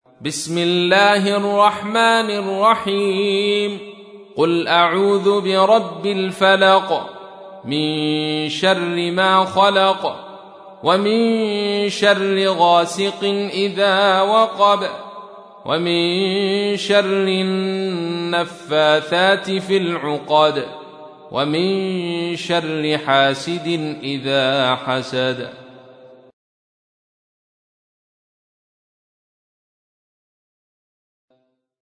تحميل : 113. سورة الفلق / القارئ عبد الرشيد صوفي / القرآن الكريم / موقع يا حسين